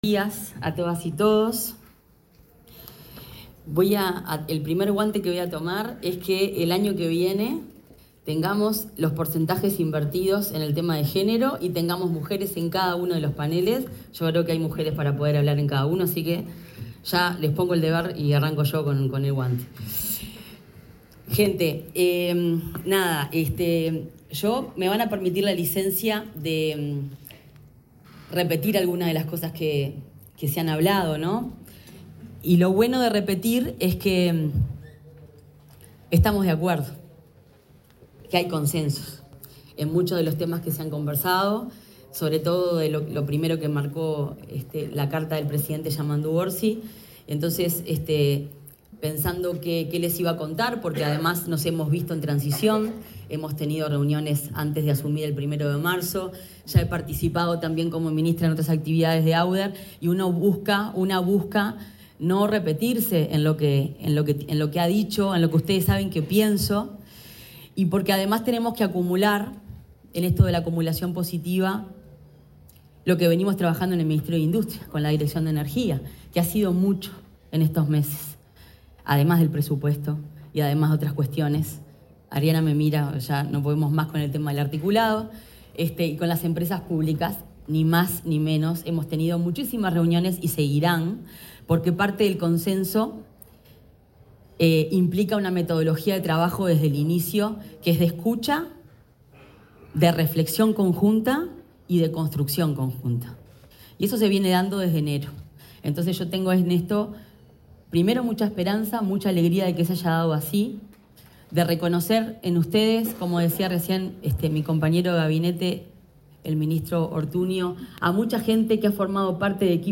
Palabras de la ministra de Industria, Fernanda Cardona
La ministra de Industria, Energía y Minería, Fernanda Cardona, expuso en la apertura del XI Congreso Latam Renovables: Energía Inteligente, que se